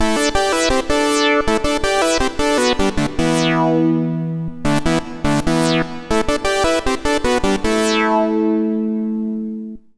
MASS RIFF.wav